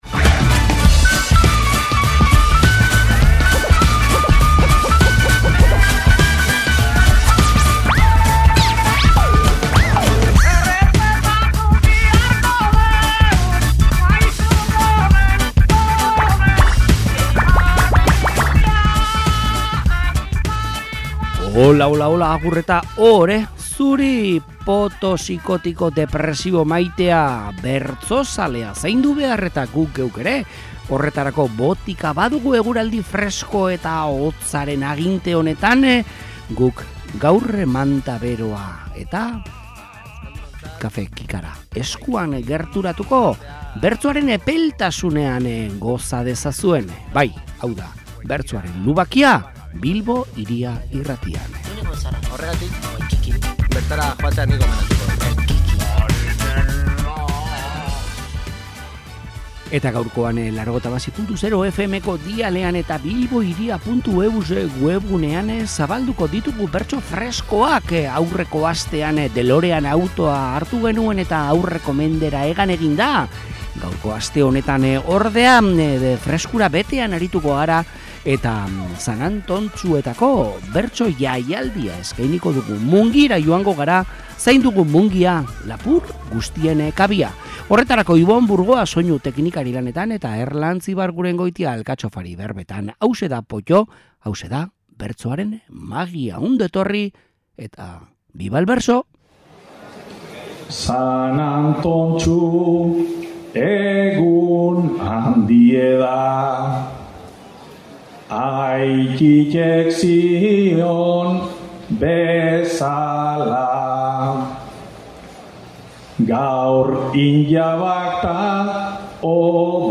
Mungia bisitatu dugu; zain dugu San Antontxuetako urteroko zita Urtarrilaren 17an, jai eta azoka handiaren bezperan Mungiako udaletxe aurreko plazan entzulez lepo izan genuen bertsoaren magiaz gozatzeko prest